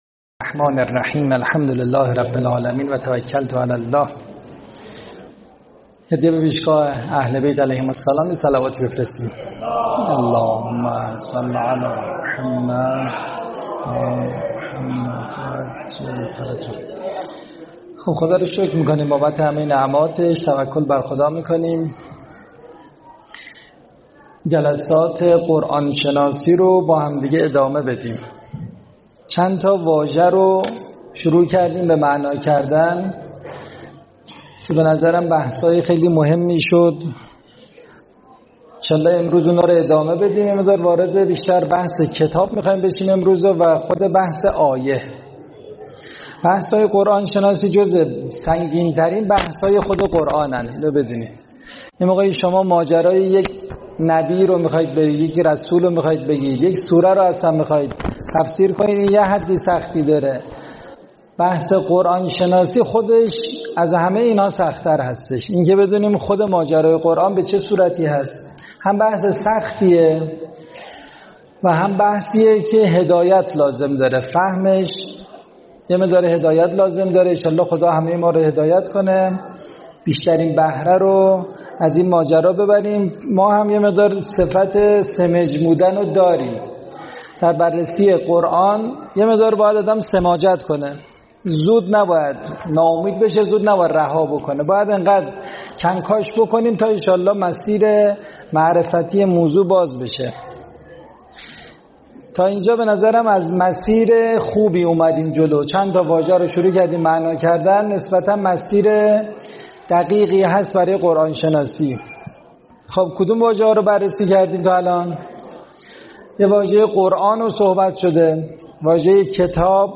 سخنرانی
فایل صوتی سخنرانی